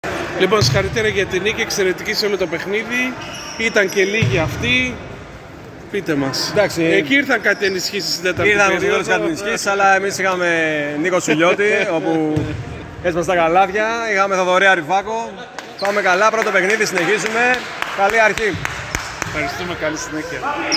GAMES INTERVIEWS